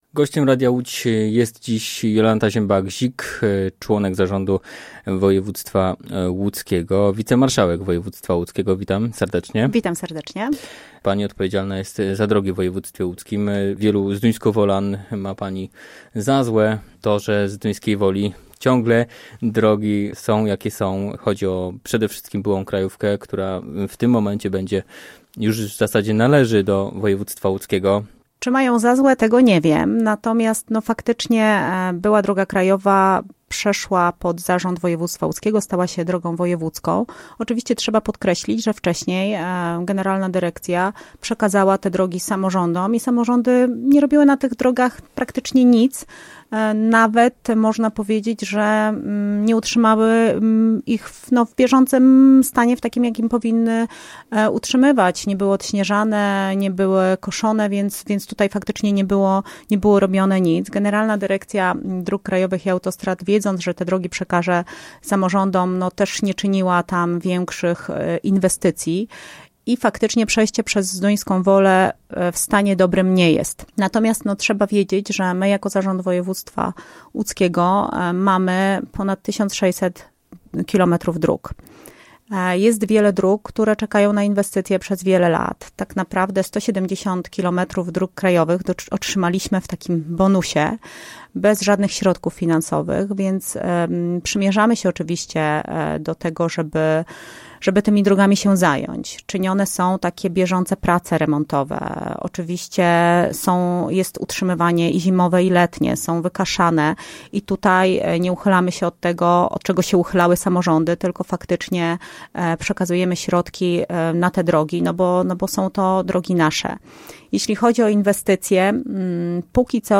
Gościem Radia Łódź Nad Wartą była wicemarszałek województwa łódzkiego, Jolanta Zięba-Gzik.